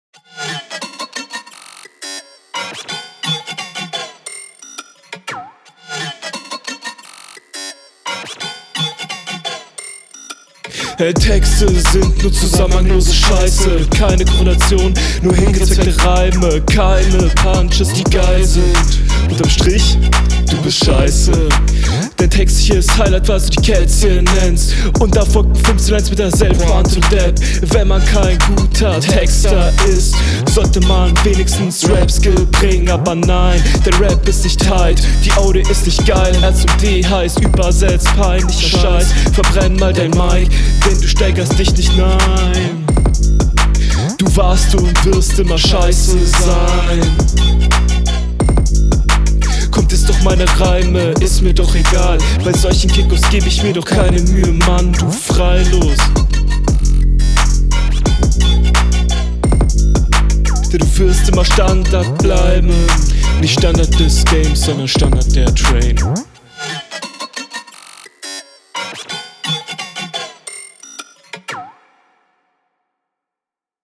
Sound bisschen geschwächelt aber immer noch ok.
Verrückter beat 😀 kommst einigermaßen mit zurecht, ziehst aber die lines so lang finde das …
Wieder bessere Qualität.